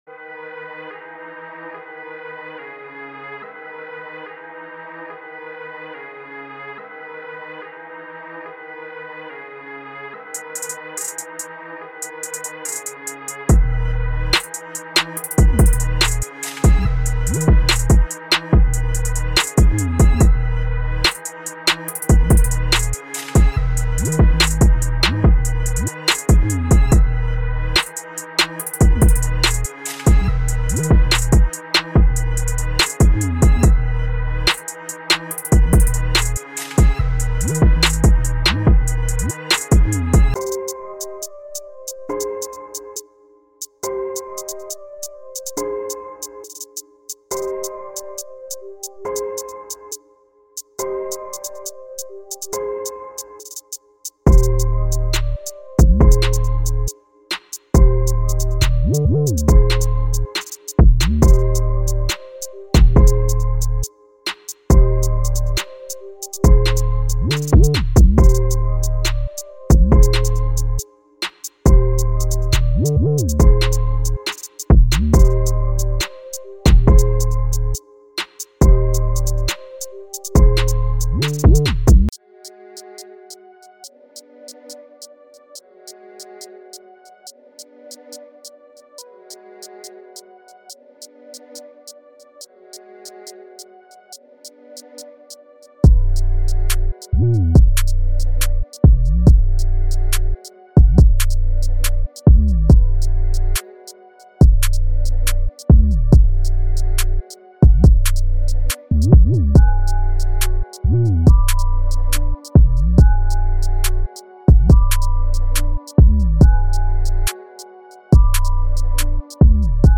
• Mini Construction Kit
• Includes Drums